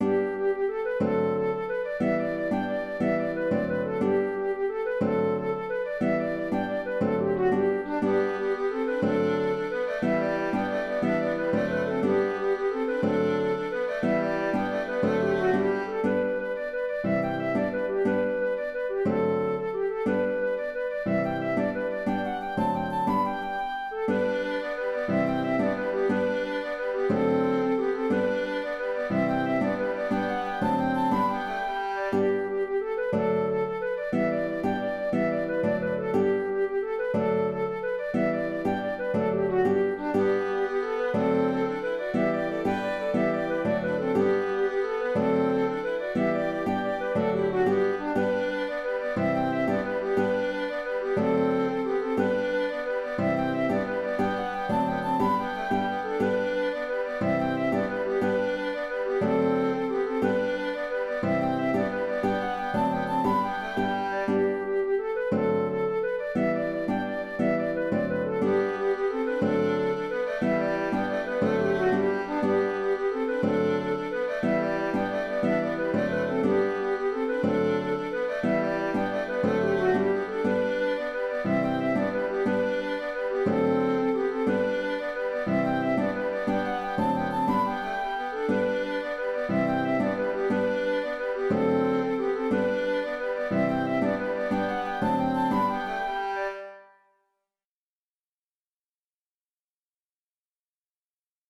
Kesh Jig (The) (Jig) - Musique irlandaise et écossaise
Auteur : Trad. Irlande.